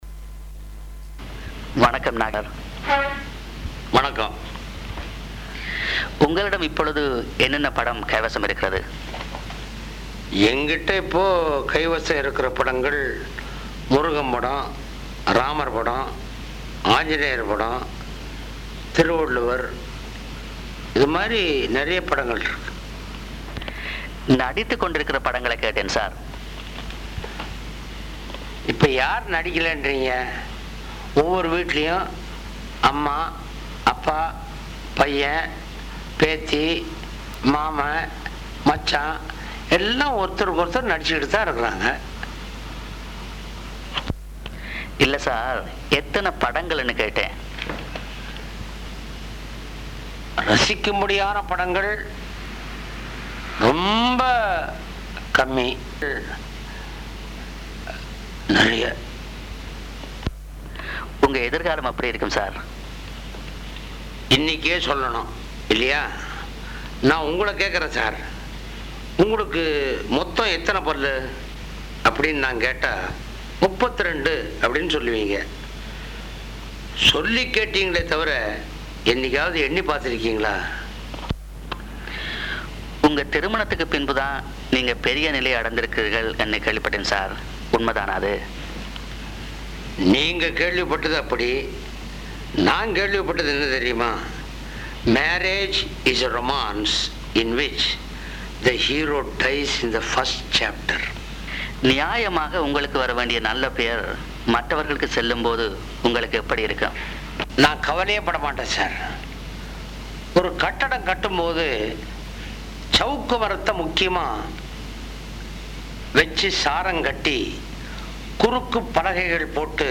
நாகேஷ் குரலைக் கேட்கலாம்.